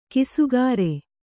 ♪ kisugāre